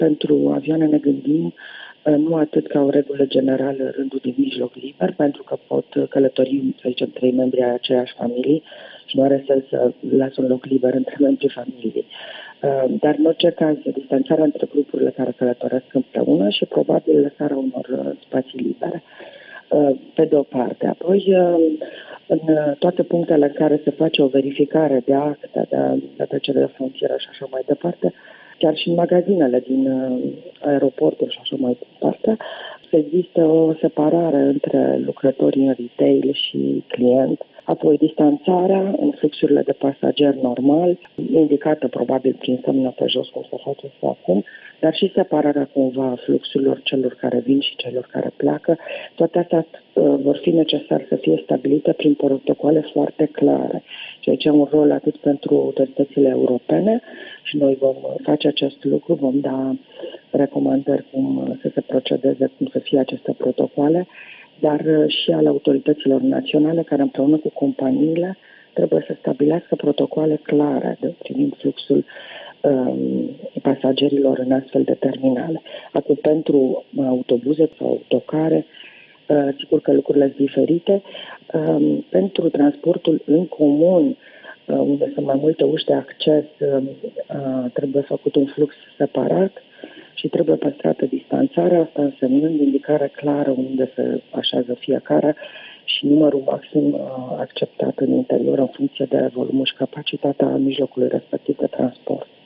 Comisar european pentru Transporturi, Adina Vălean a vorbit despre regulile care vor fi impuse domeniului în momentul în care vor fi relaxate restricțiile impuse în contextul crizei sanitare declanșate de noul coronavirus.